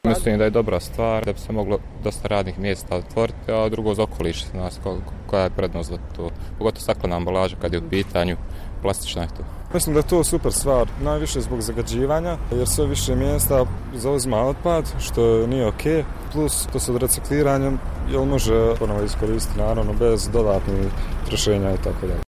I građani Sarajeva svjesni su prednosti reciklaže: